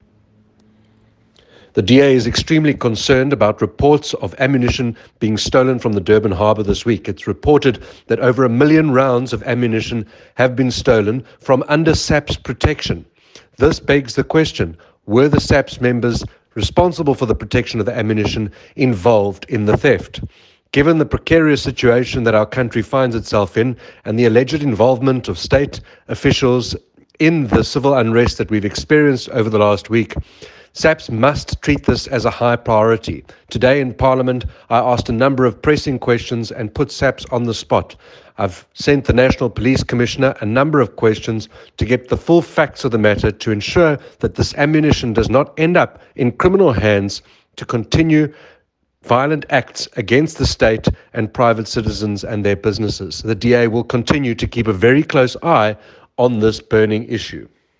Issued by Andrew Whitfield MP – DA Shadow Minister of Police
soundbite by Andrew Whitfield MP.